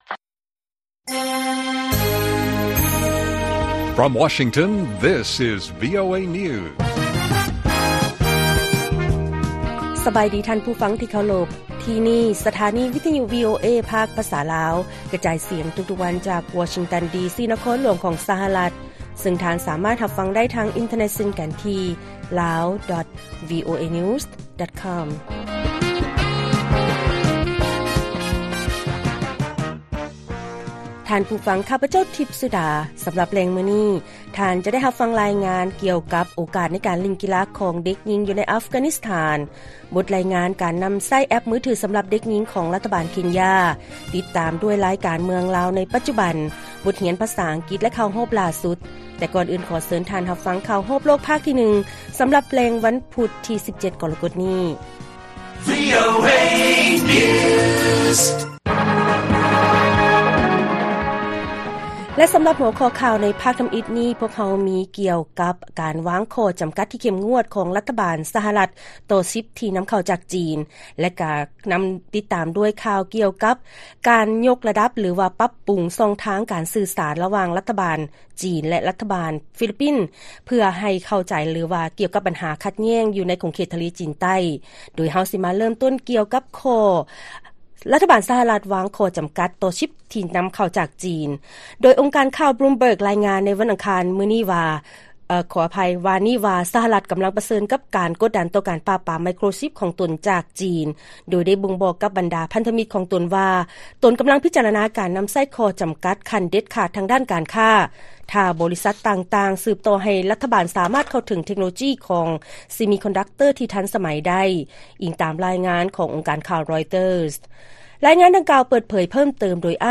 ລາຍການກະຈາຍສຽງຂອງວີໂອເອ ລາວ: ແອັບ ຂອງລັດຖະບານ ເຄັນຢາ ໃຫ້ຂໍ້ມູນກັບບັນດາເດັກຍິງ ກ່ຽວກັບ ການມີປະຈຳເດືອນ ເຊິ່ງເປັນຫົວຂໍ້ຕ້ອງຫ້າມ.